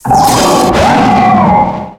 Cri de Fulguris dans sa forme Totémique dans Pokémon X et Y.
Cri_0642_Totémique_XY.ogg